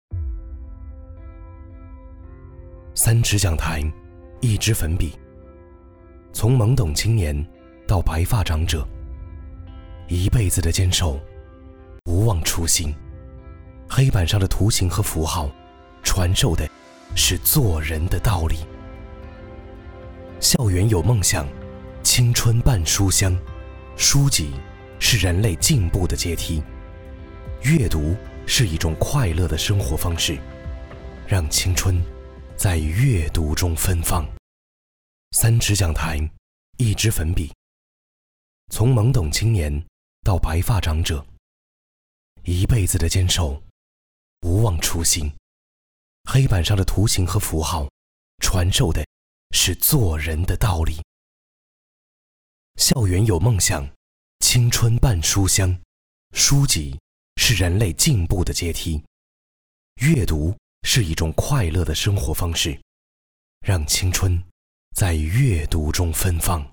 独白：